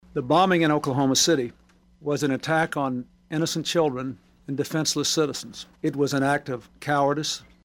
CLICK HERE to listen to commentary from President Clinton.